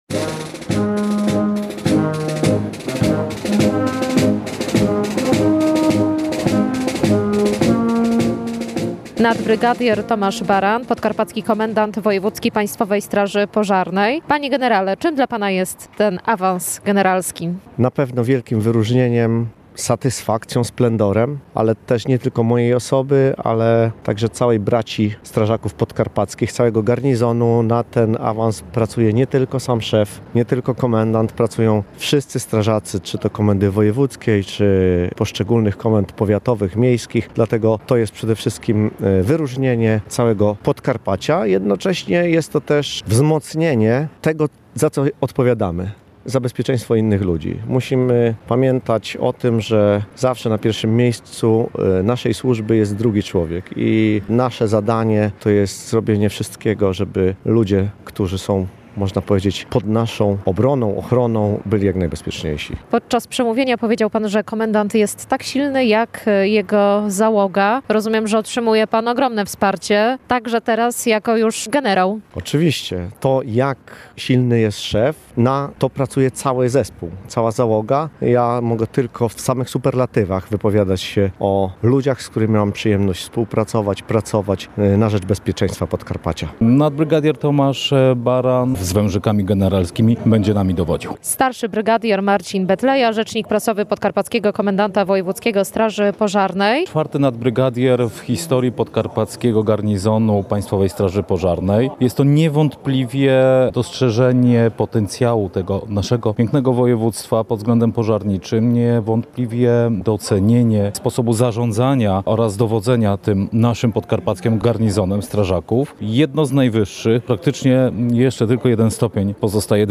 Uroczysty apel w Rzeszowie po generalskiej nominacji
W poniedziałek, 5 maja, w Rzeszowie odbył się uroczysty apel z udziałem nadbrygadiera Tomasza Barana, Podkarpackiego Komendanta Wojewódzkiego Państwowej Straży Pożarnej.